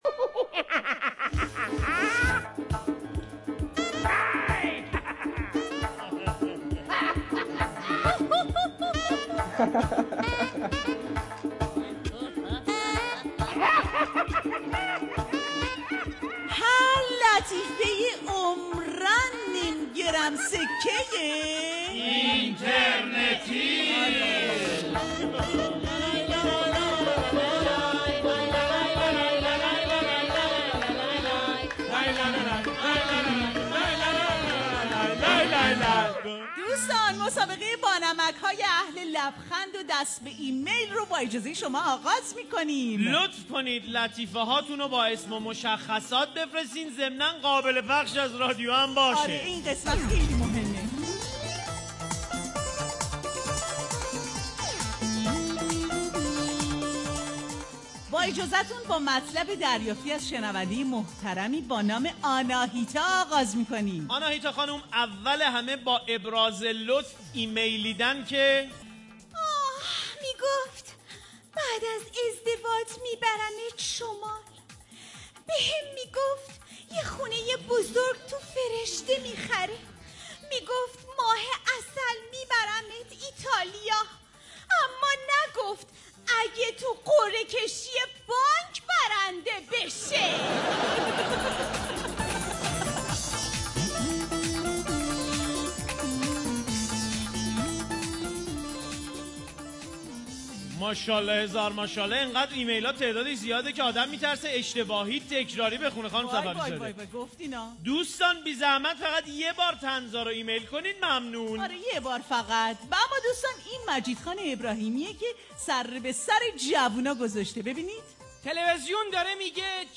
برنامه طنز رادیو ایران-جمعه ایرانی